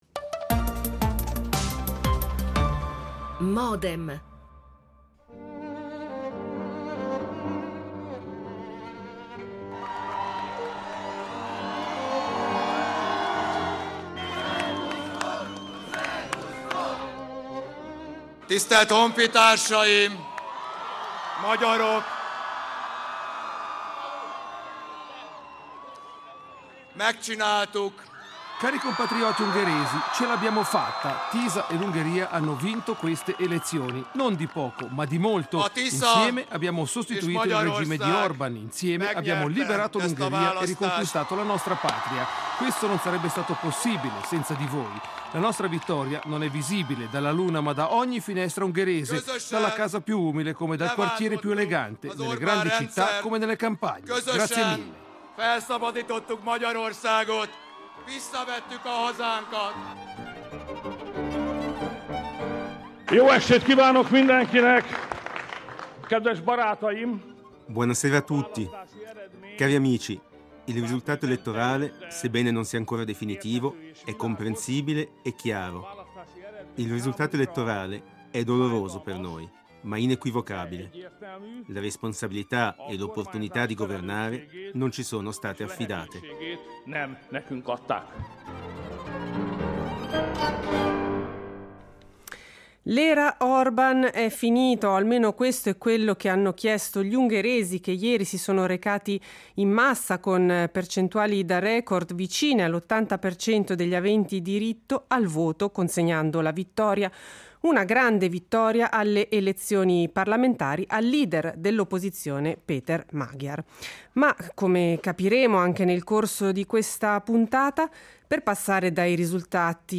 Ne parliamo con tre ospiti:
L'attualità approfondita, in diretta, tutte le mattine, da lunedì a venerdì